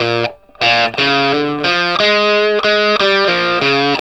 WALK1 60 BF.wav